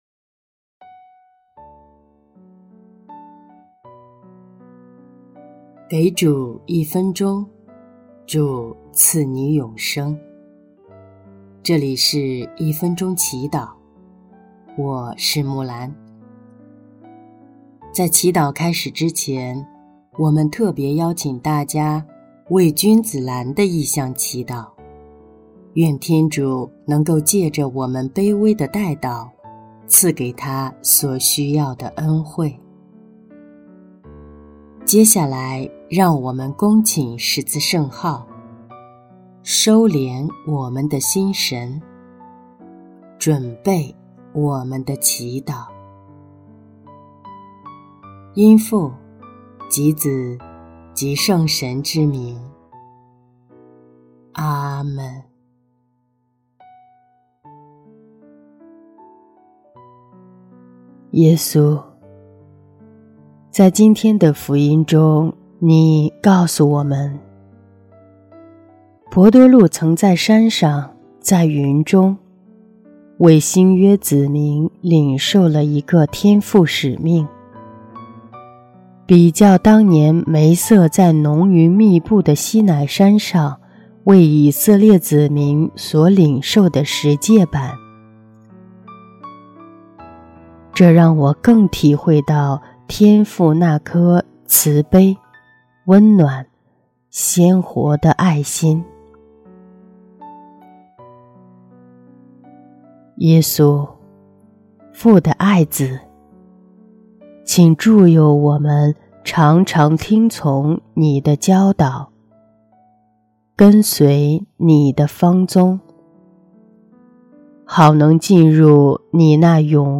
音乐： 第二届华语圣歌大赛参赛歌曲《上主的圣言》